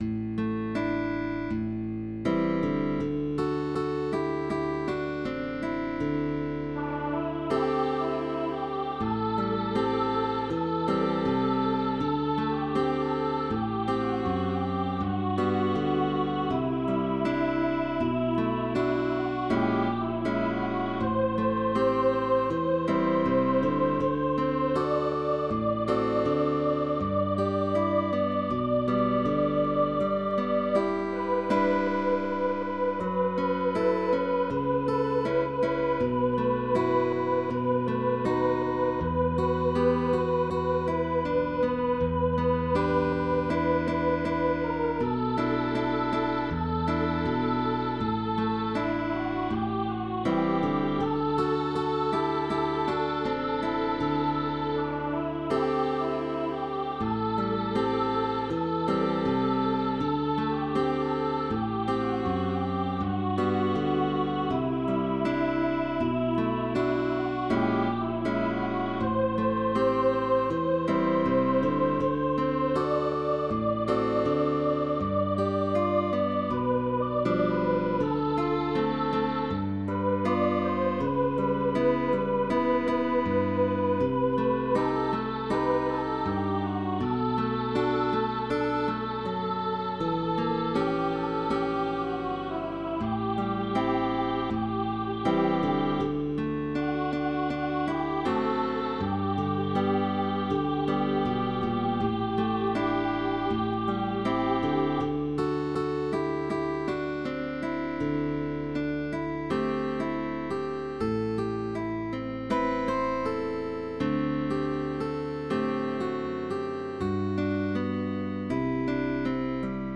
Pop Songs USA